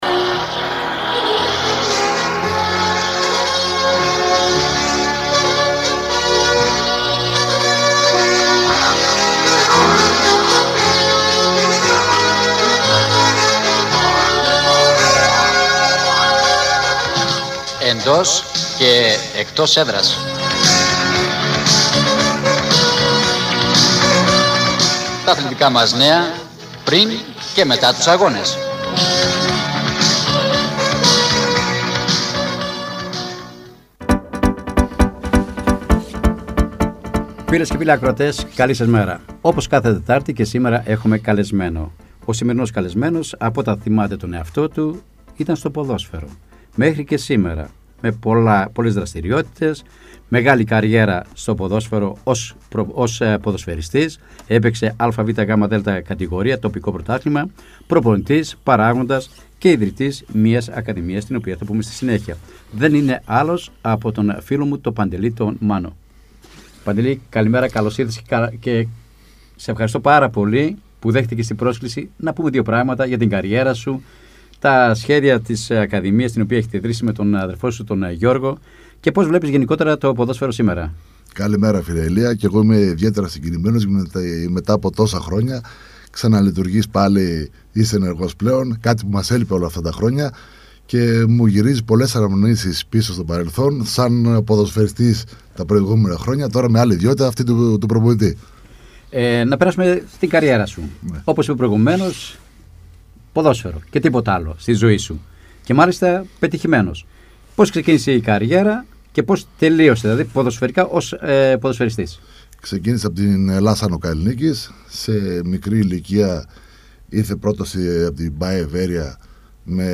“Εντός και Εκτός Έδρας” Εβδομαδιαία αθλητική εκπομπή με συνεντεύξεις και ρεπορτάζ της επικαιρότητας, στην περιφερειακή Ενότητα Φλώρινας.